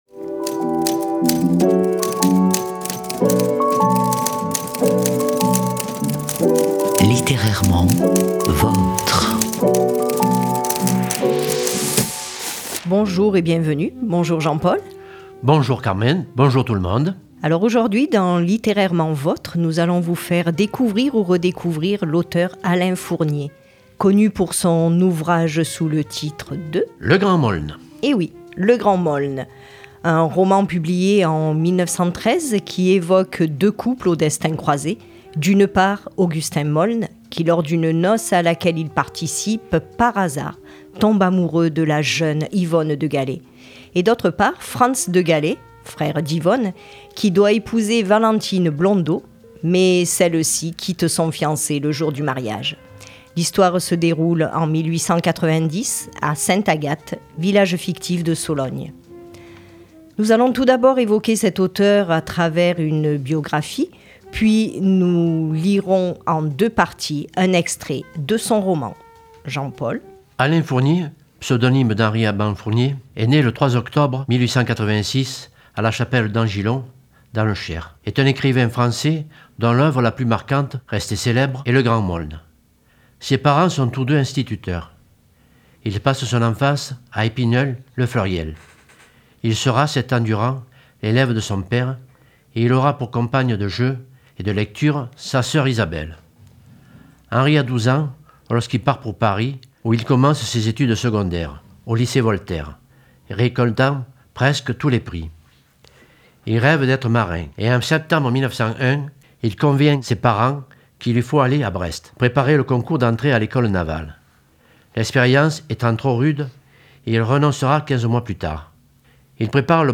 Cette émission est consacrée à Alain Fournier. Extrait lecture de : Le Grand Meaulnes est un roman d'Alain Fournier publié en 1913.